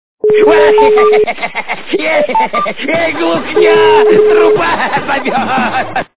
» Звуки » Смешные » Идиотский смех - Эй, глухня. Труба зовет!
Звук Идиотский смех - Эй, глухня. Труба зовет!